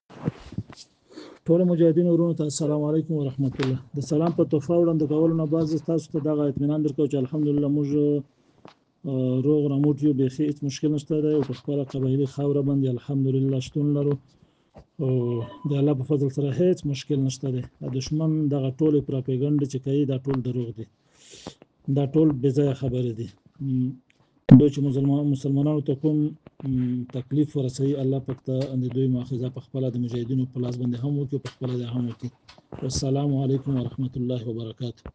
ځینو دا هدف د ټي ټي پي مشر نور ولي بللی خو نورولي په یوه صوتي پیغام کې ویلي چې روغ رمټ دی او دا یې د دښمنانو تبلیغات بللي دي. (د مفتي نور ولي څرګندونې)